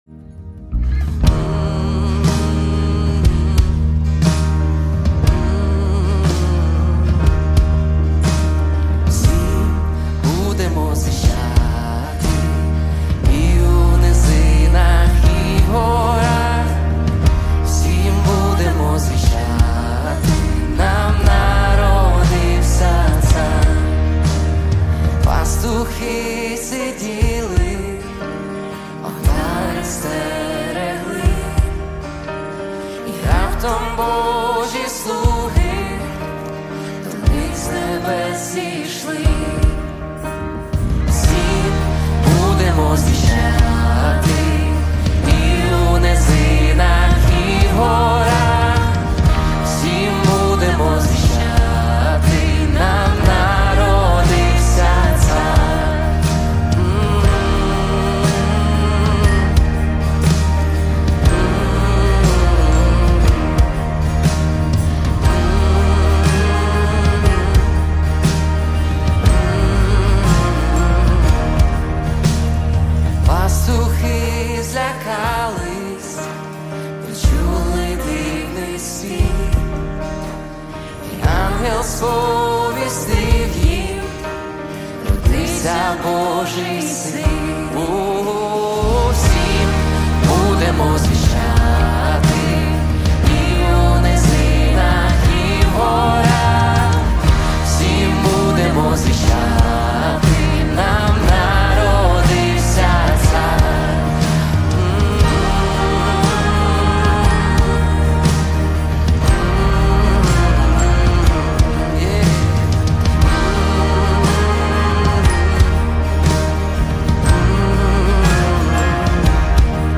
159 просмотров 19 прослушиваний 2 скачивания BPM: 60